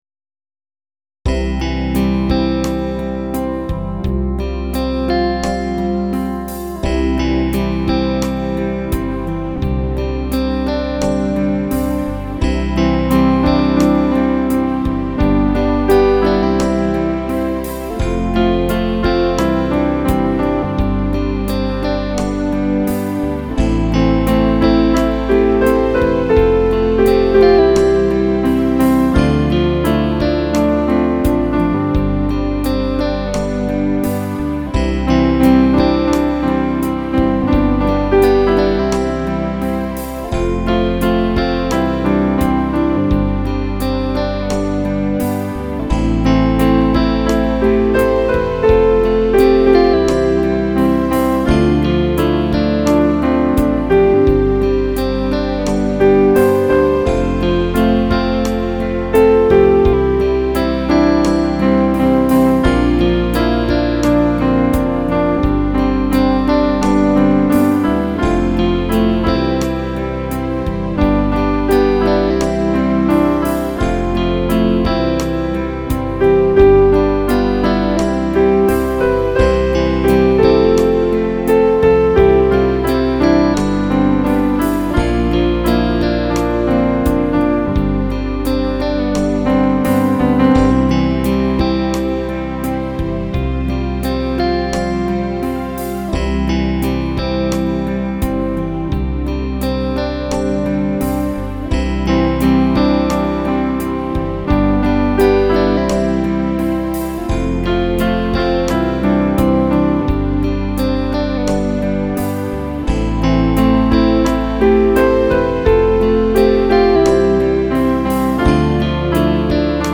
Instrumentalaufnahme